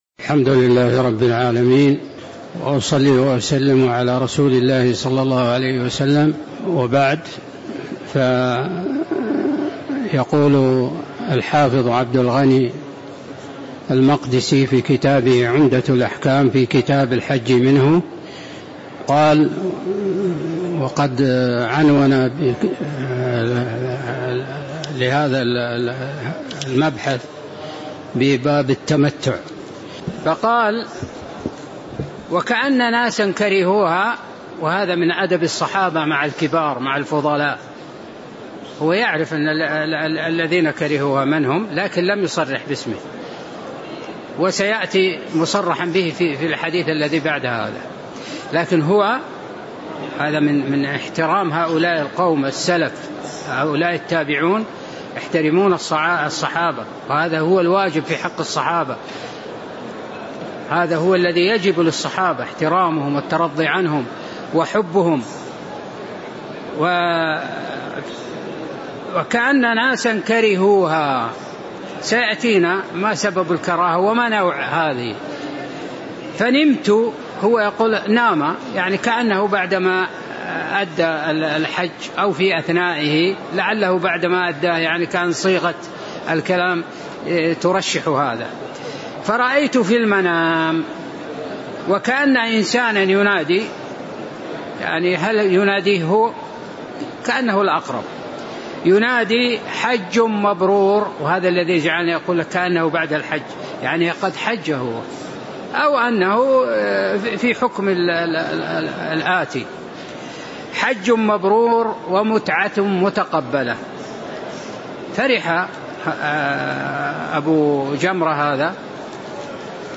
تاريخ النشر ٣ ذو الحجة ١٤٤٣ هـ المكان: المسجد النبوي الشيخ